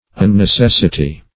unnecessity - definition of unnecessity - synonyms, pronunciation, spelling from Free Dictionary
Search Result for " unnecessity" : The Collaborative International Dictionary of English v.0.48: Unnecessity \Un`ne*ces"si*ty\, n. The state of being unnecessary; something unnecessary.